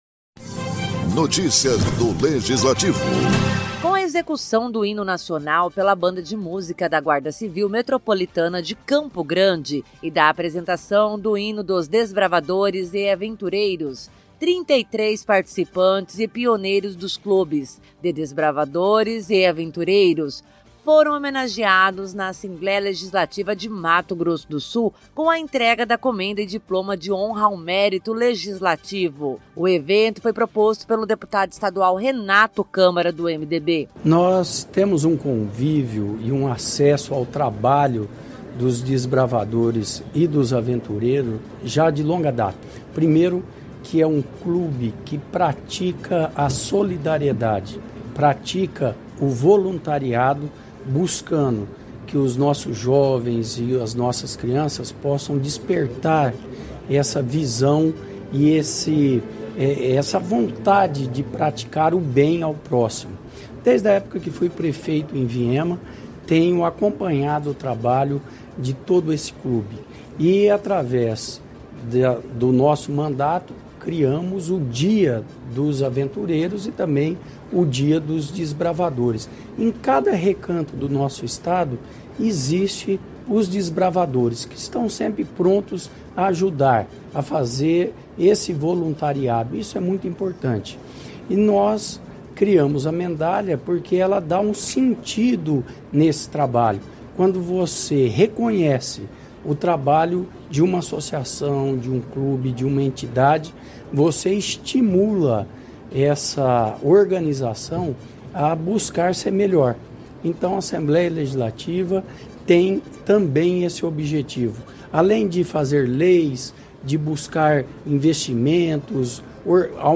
Em sessão solene a Assembleia Legislativa de Mato Grosso do Sul entregou a Comenda e Diploma de Honra ao Mérito Legislativo a 33 participantes e pioneiros dos Clubes de Desbravadores e Aventureiros do Estado.